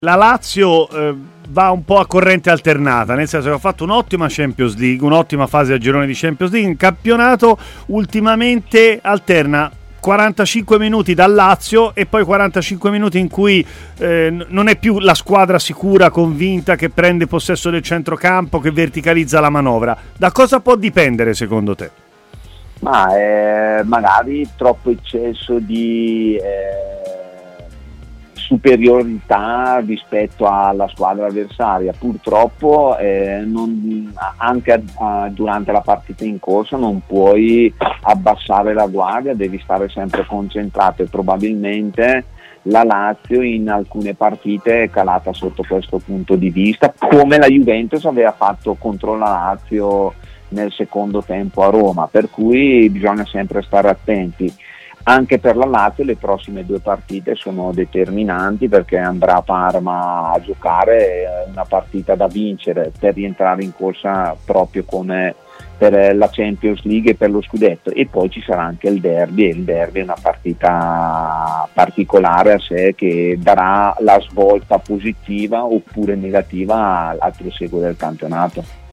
L'ex Lazio, intervenuto ai microfoni di TMW Radio, si è espresso anche sui biancocelesti e sul momento che stanno vivendo in campionato.